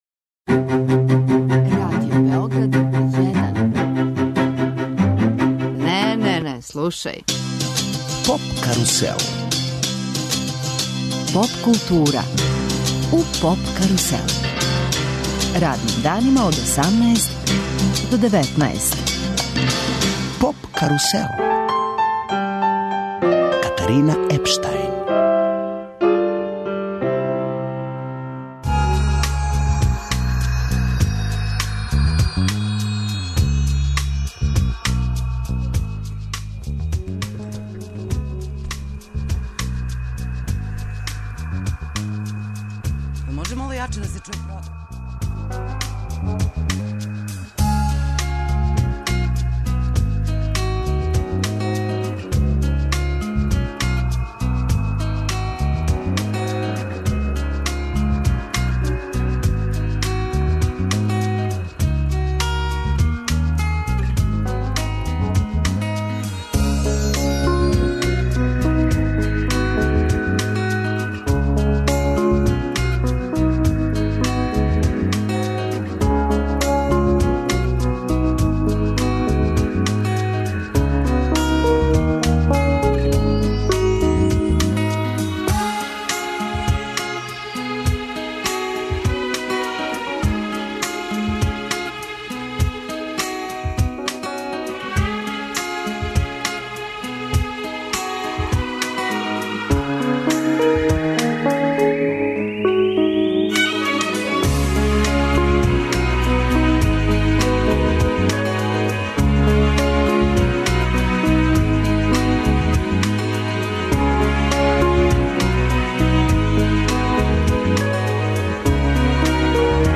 наступиће уживо у Студију 6